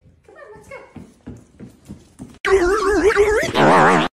distorted brain fart meme Meme Sound Effect
distorted brain fart meme.mp3